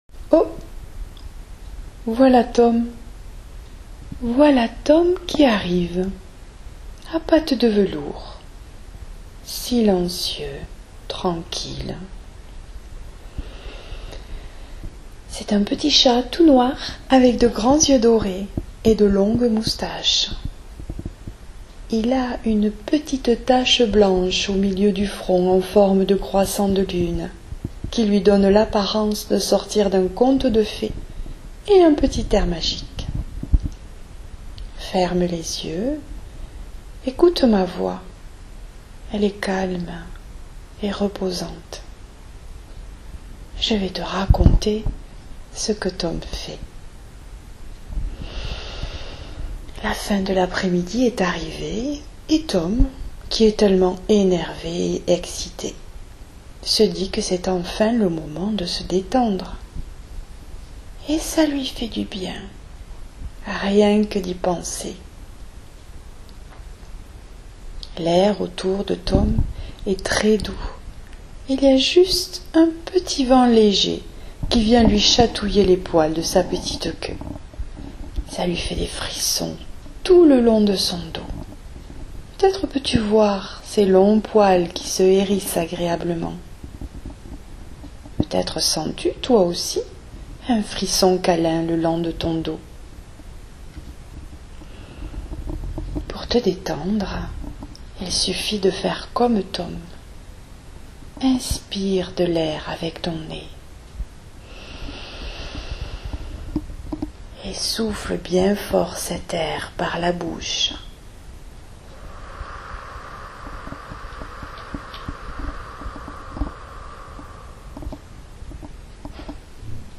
Détente avec Tom – Séance de sophrologie pour enfant